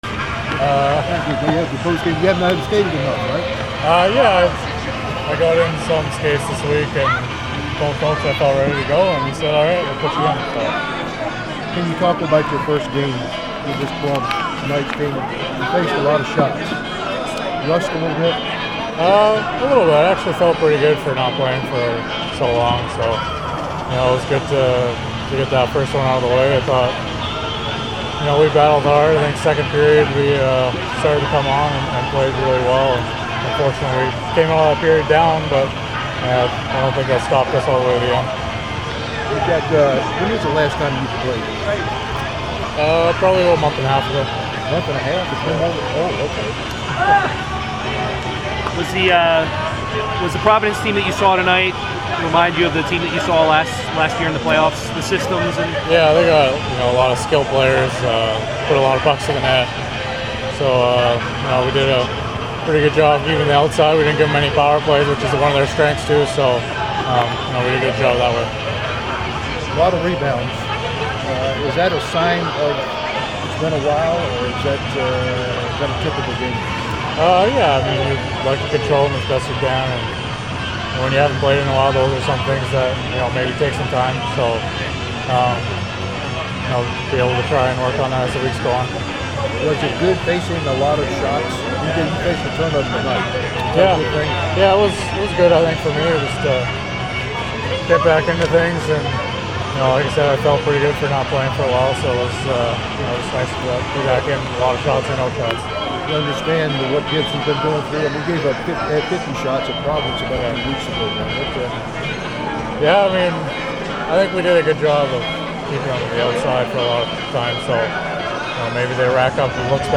If you like Van Halen and KT Tunstall, you are in for a treat too, as the Admirals had a post game skate last night after their 4-3 loss to the Providence Bruins and those artists were playing at the time loudly in the background.